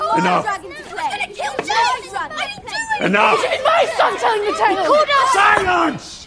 Silence Sound Effect Free Download
Silence